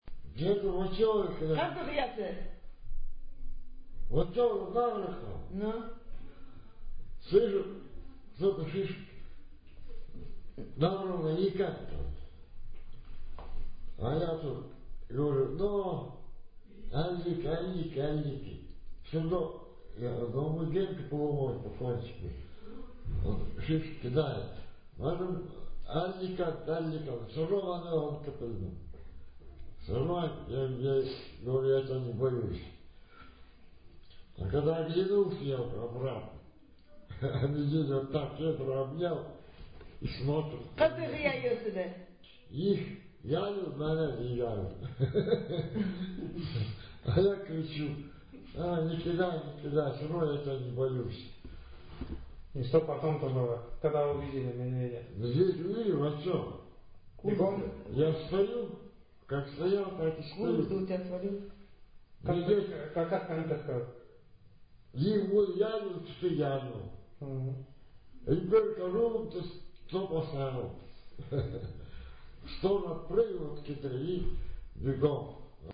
These Eastern Khanty texts were recorded in 2007 in the upper and lower Vasyugan river areas, and in the Alexandrovo Ob’ river communities. The texts were narrated by the male and female Vasyugan Khanty and Alexandrovo Khanty speakers to other Khanty speakers and to the researchers, who also spoke limited Khanty and offered occasional interjections to the narration.